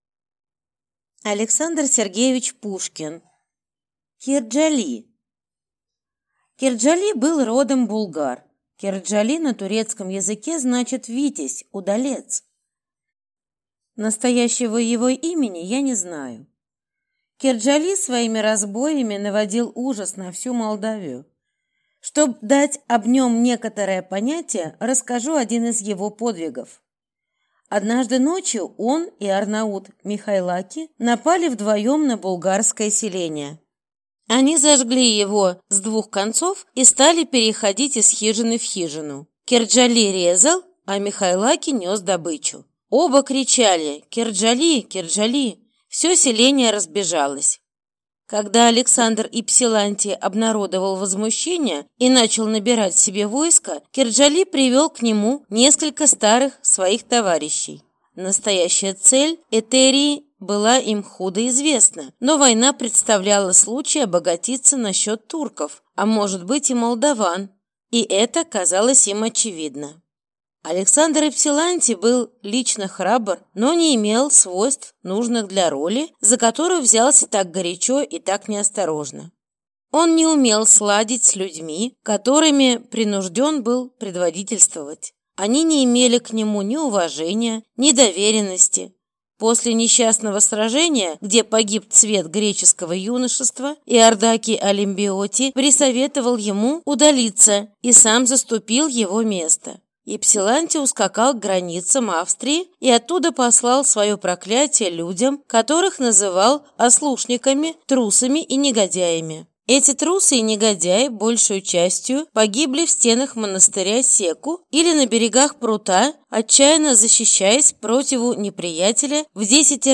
Аудиокнига Кирджали | Библиотека аудиокниг